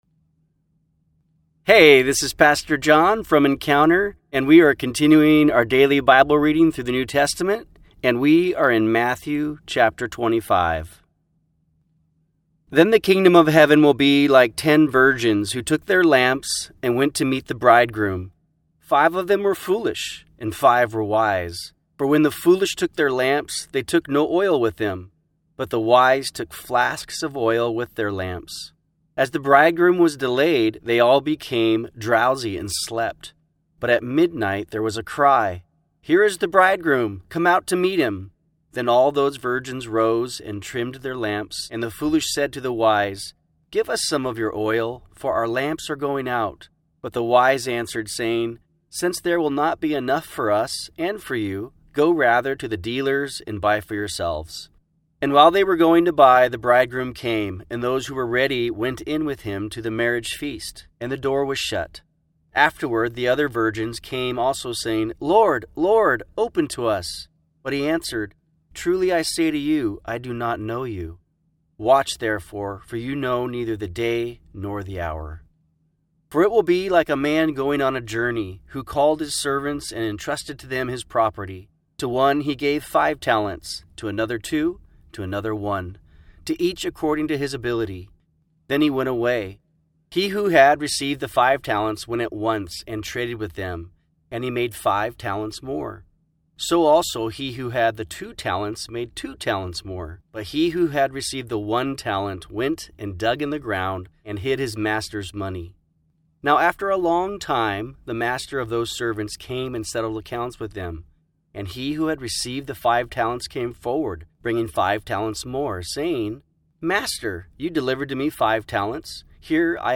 New Testament Bible Reading Plan – Audio Version